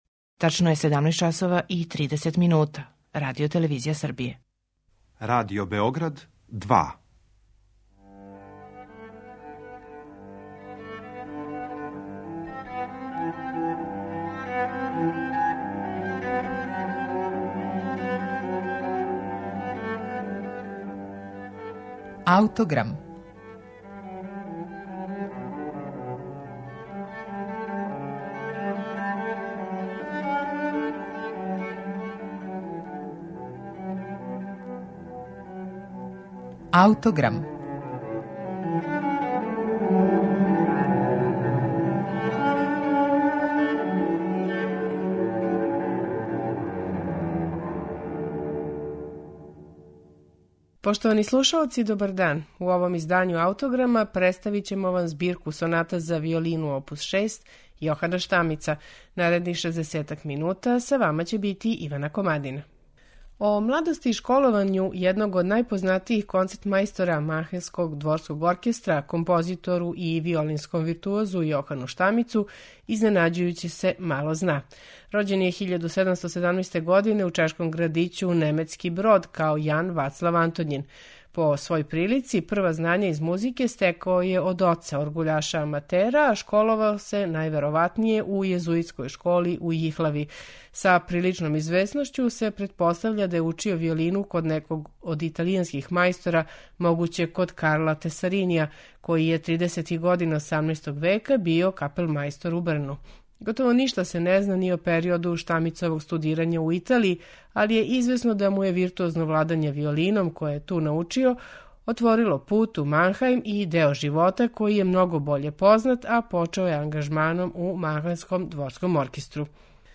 Представићемо Виолинске сонате опус 6 Јохана Штамица